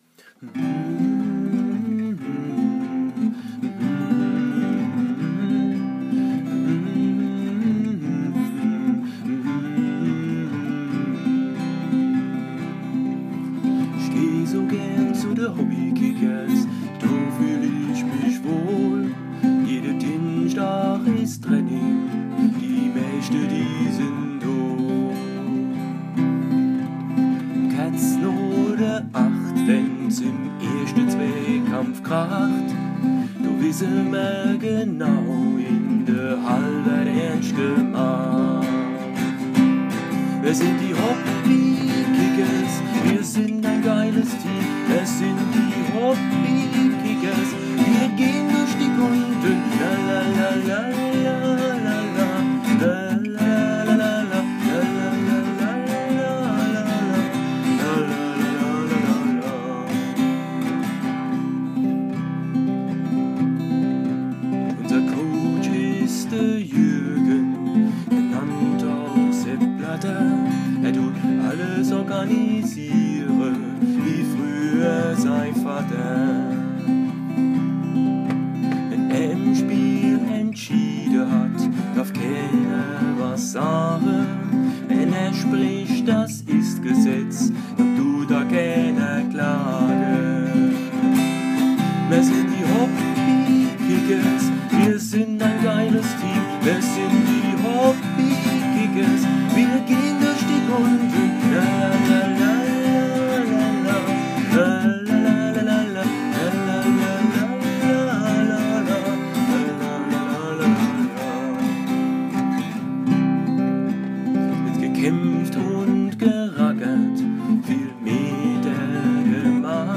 Unser Vereinslied
Auf unserer Mannschaftsfahrt im Juni 2012 nach Tschechien hatten wir in Bierlaune die Idee, ein Vereinslied zu texten. Unter Gitarrenbegleitung wurde das Lied dann nach Rückkehr von einem unserer Spieler eingesungen.